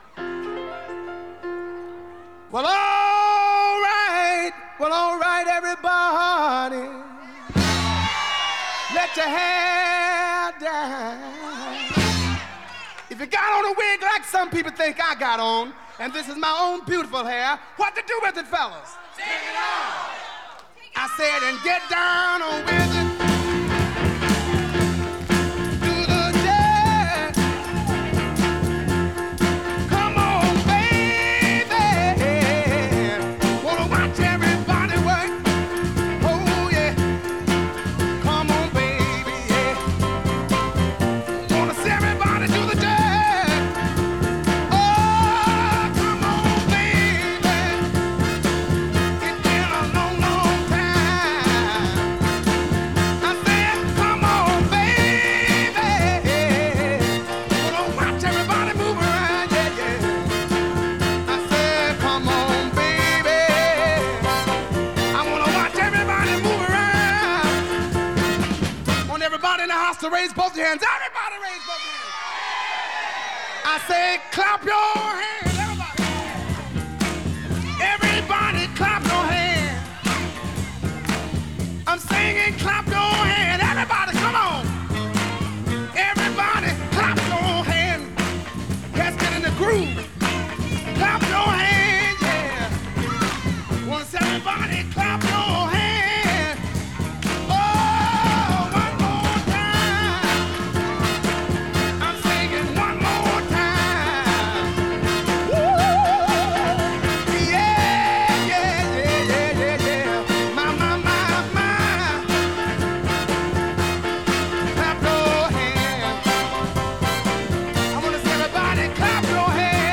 Жанр Рок-н-ролл, госпел